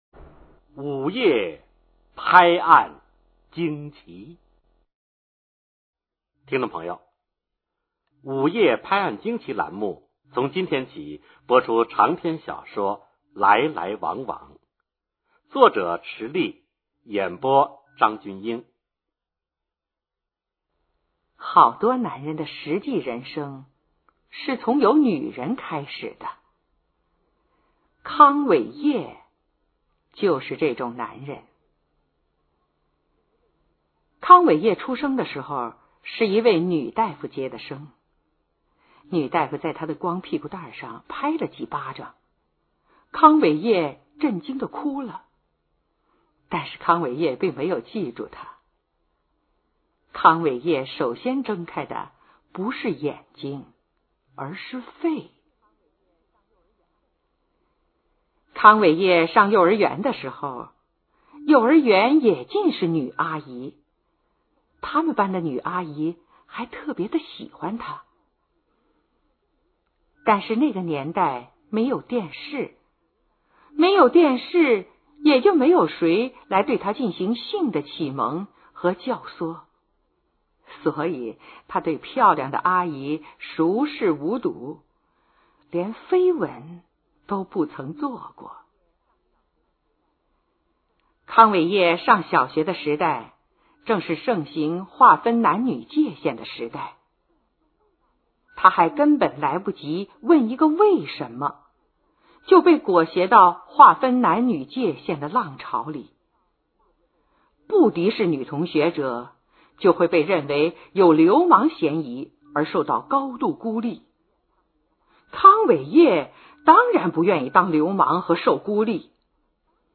[长篇小说]来来往往(声频版) 作者:池莉;朗读:张筠英